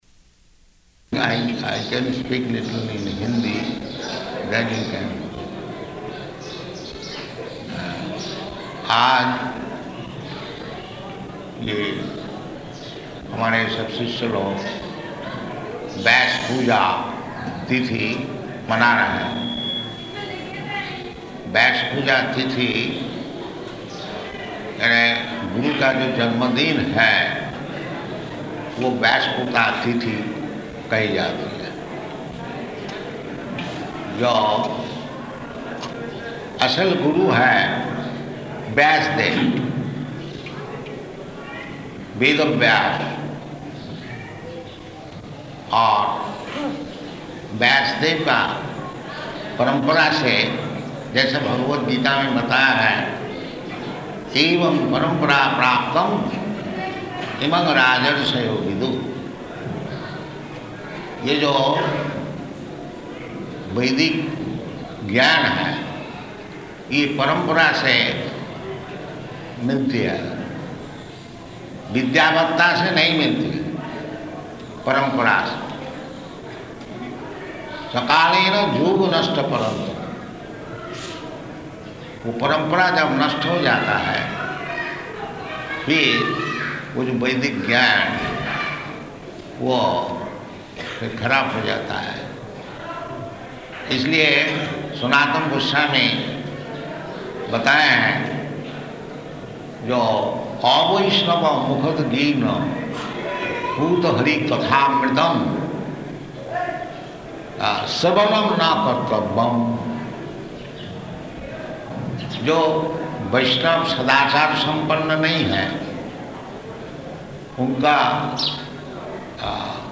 Lecture -- [Hindi--final proofreading pending]
Lecture -- [Hindi--final proofreading pending] --:-- --:-- Type: Lectures and Addresses Dated: August 31st 1975 Location: Vṛndāvana Audio file: 750831LE.VRN.mp3 Prabhupāda: I can speak a little in Hindi then you can.